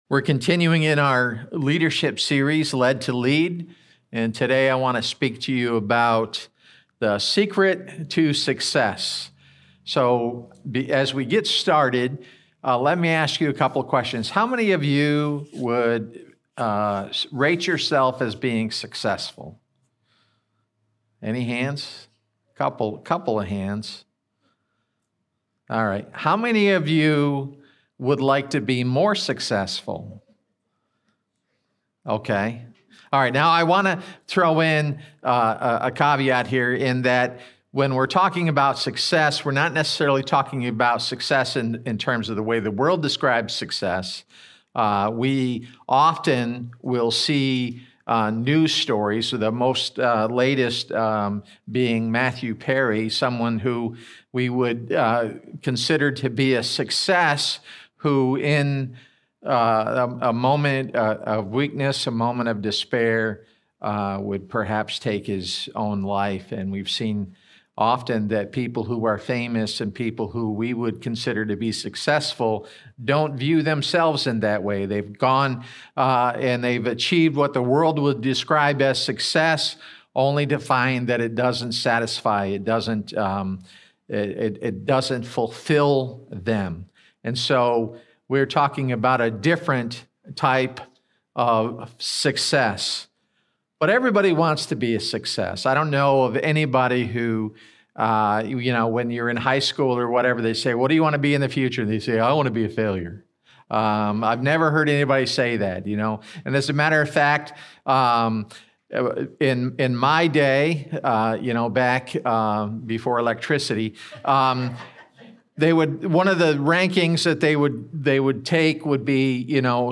Current Message